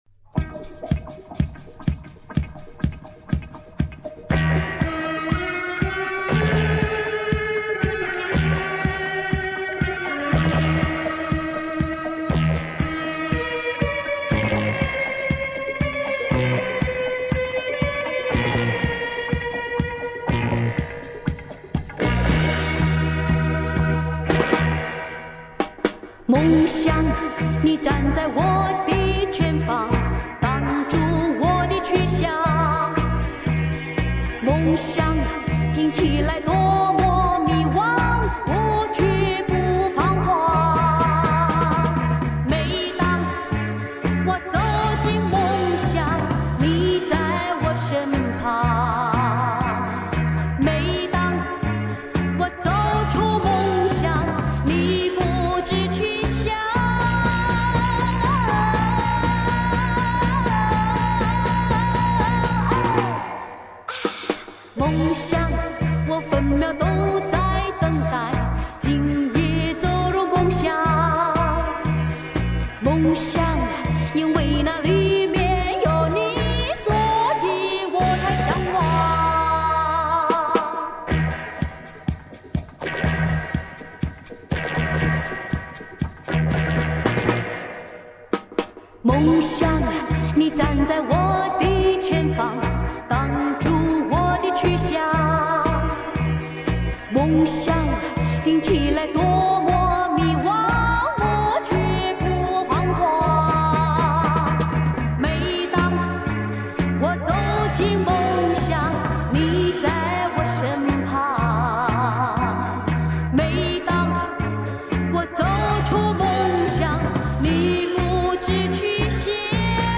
LP